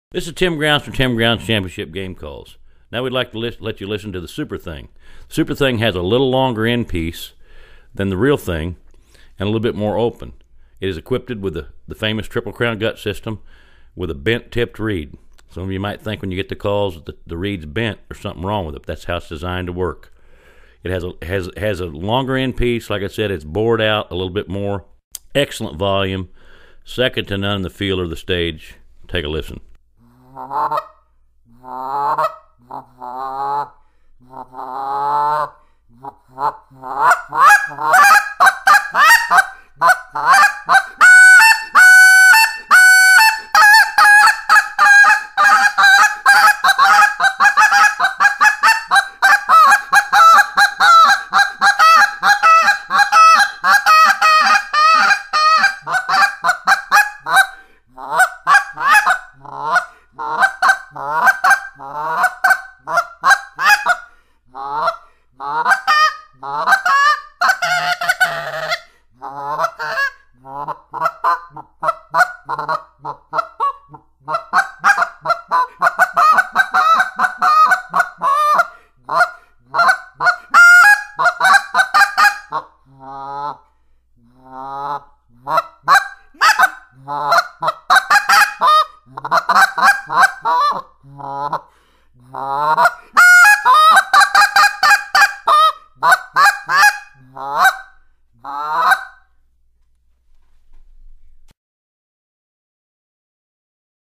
Goose Calls
This short reed call has the nasty low end of the REAL THANG and the full power of the SUPER MAG.